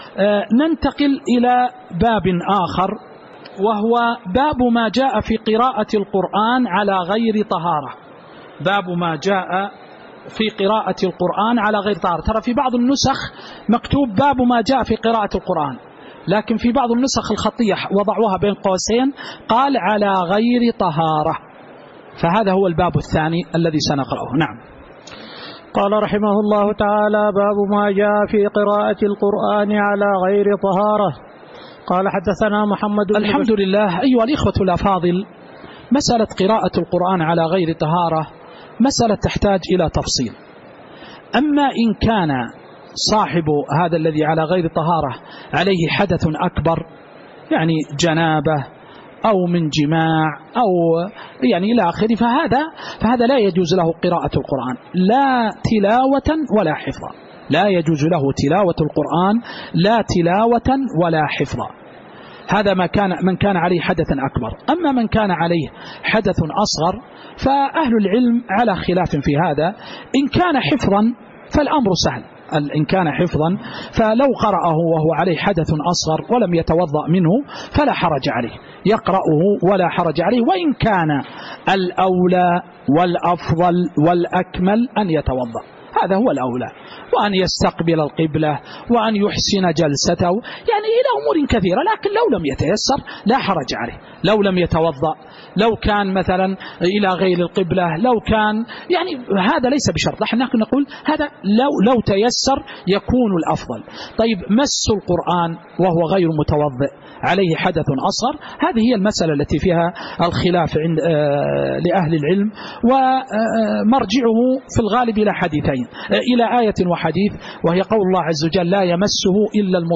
تاريخ النشر ١٤ شعبان ١٤٤٤ هـ المكان: المسجد النبوي الشيخ